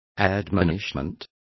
Complete with pronunciation of the translation of admonishments.